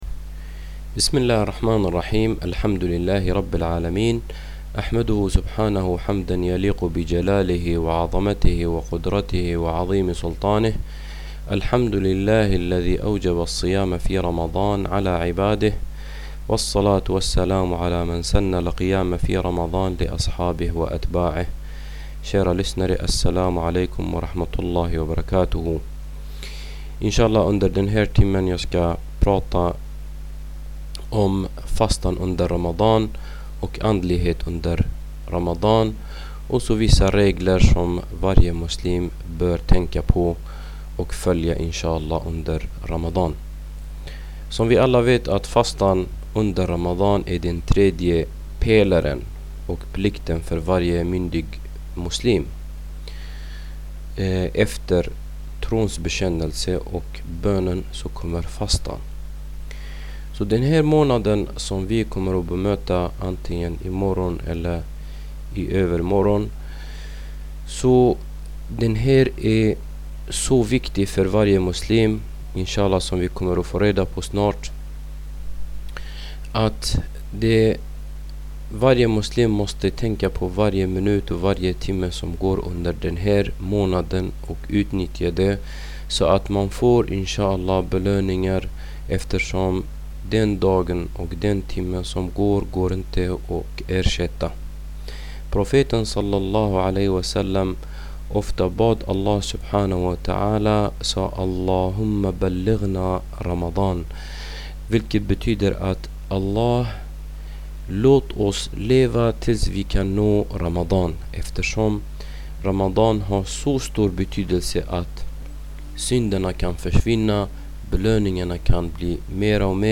En föreläsning om andligheten under Ramadan fastemånad